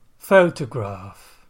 sound_loud_speaker photograph /ˈfəʊtəgrɑːf/